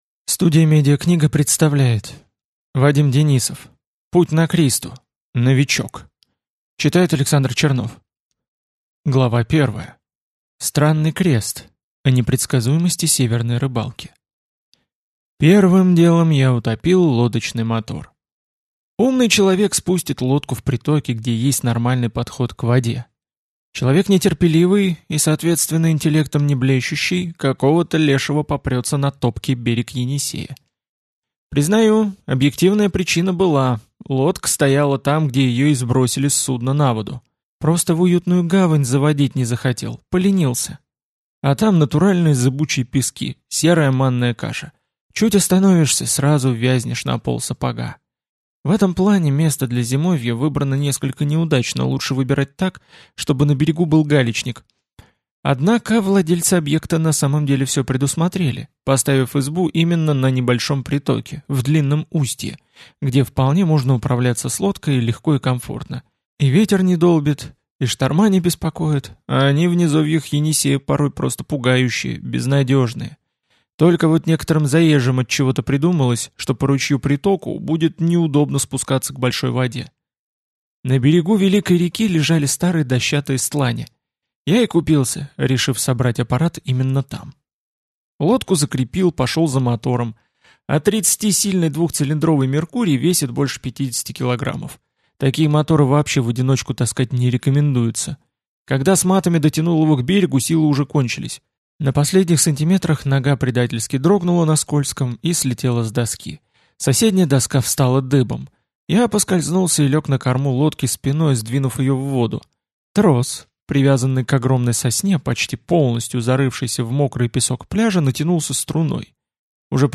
Аудиокнига Путь на Кристу. Новичок | Библиотека аудиокниг